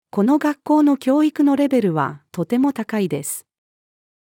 この学校の教育のレベルはとても高いです。-female.mp3